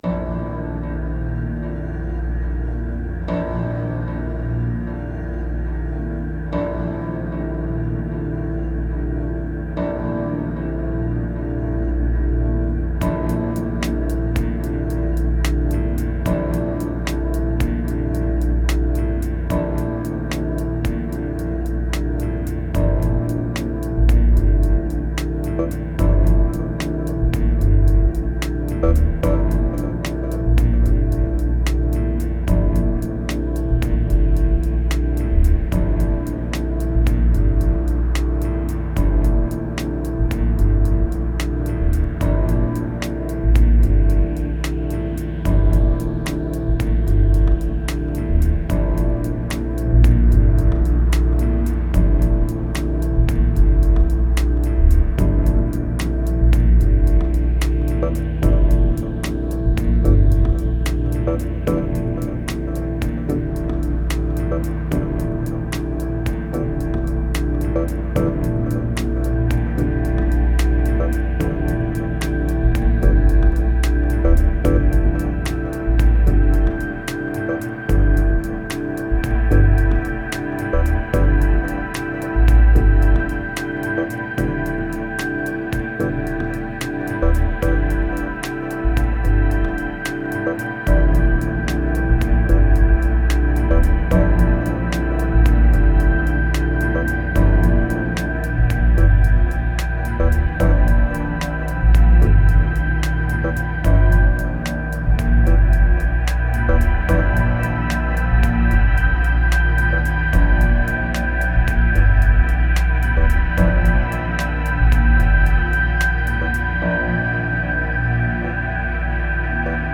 775📈 - 94%🤔 - 78BPM🔊 - 2021-02-16📅 - 805🌟